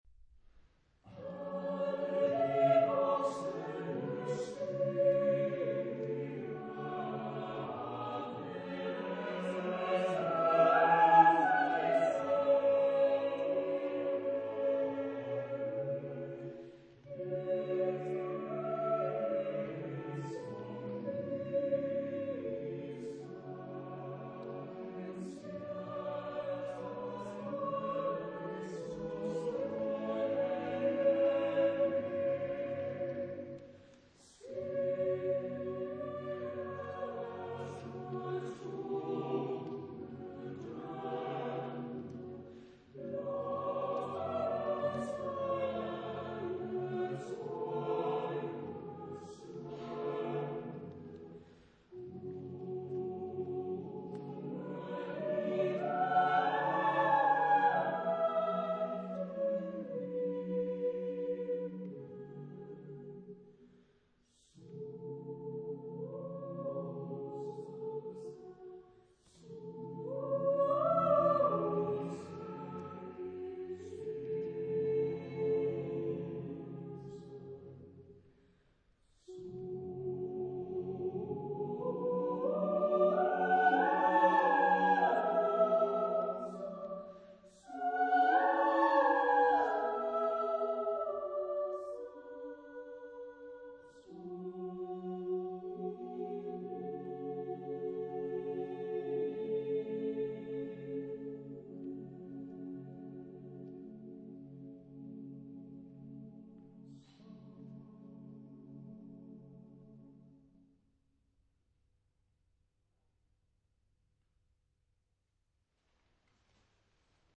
Genre-Style-Forme : Profane ; Pièce chorale ; Cycle
Type de choeur : SAATTBB  (7 voix mixtes )
Tonalité : fa dièse mineur
Réf. discographique : Internationaler Kammerchor Wettbewerb Marktoberdorf